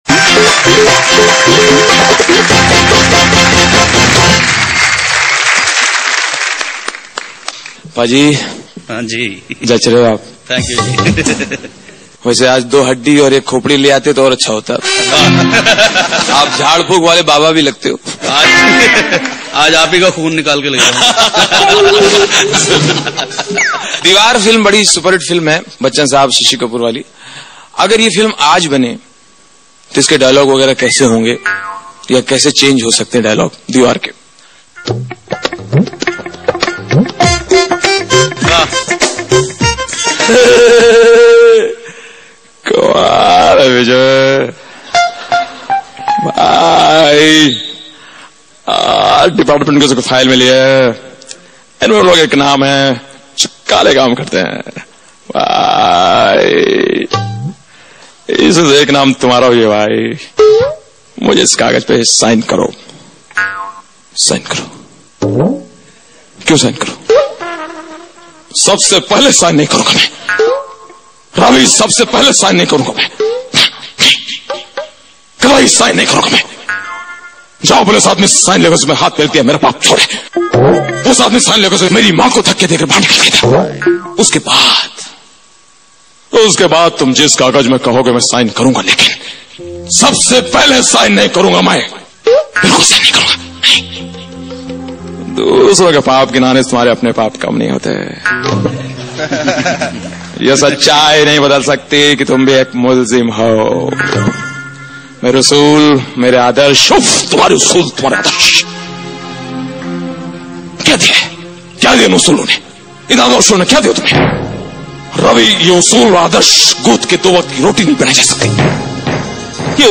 Funny Mimicry Voice